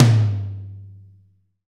Index of /90_sSampleCDs/Roland L-CD701/KIT_Drum Kits 6/KIT_Fatso Kit
TOM AC.TOM0S.wav